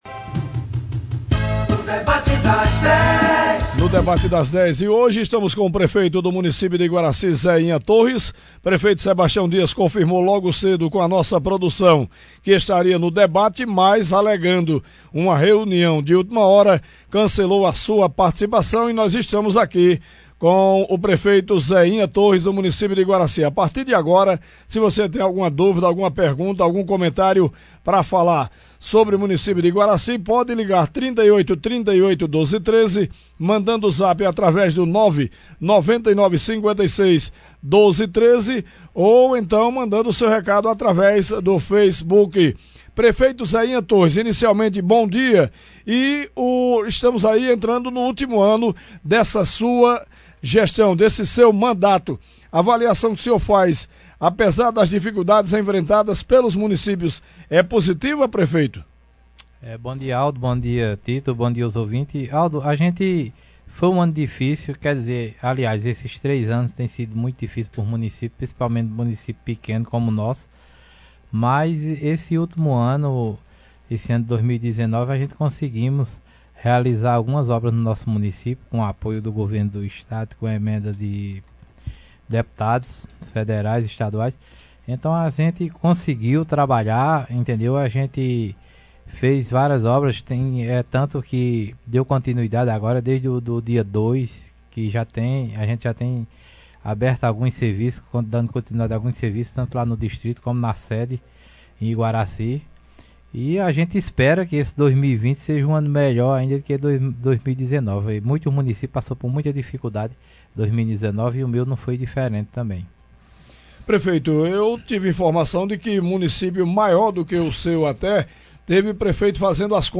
O prefeito do município de Iguaracy, Zeinha Torres, foi o convidado do Debate das Dez da Rádio Pajeú desta quarta-feira (08.01).